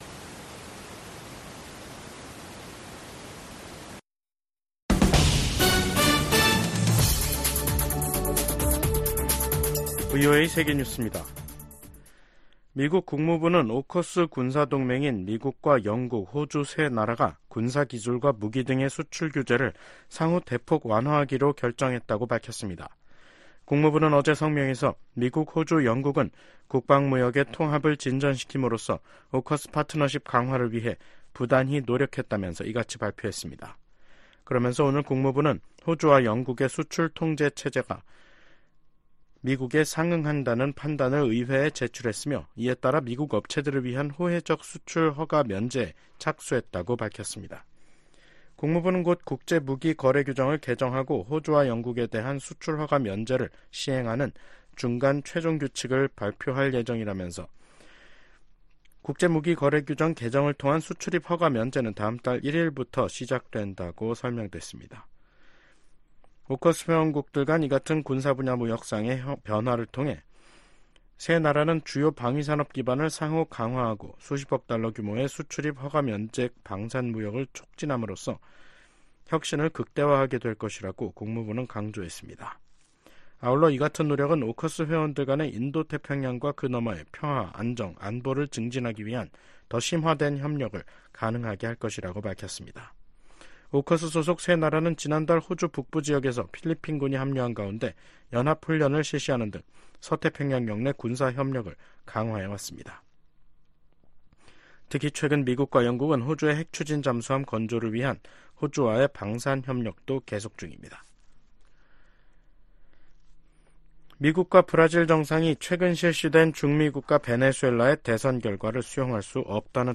VOA 한국어 간판 뉴스 프로그램 '뉴스 투데이', 2024년 8월 16일 2부 방송입니다. 미국 정부가 일본 고위 당국자들의 야스쿠니 신사 참배를 “과거 지향적”이라고 평가했습니다. 백악관 당국자가 연내 미한일 3국 정상회담 개최 가능성을 거론했습니다. 윤석열 한국 대통령이 발표한 자유에 기반한 남북 통일 구상과 전략에 대해 미국 전문가들은 “미래 비전 제시”라고 평가했습니다.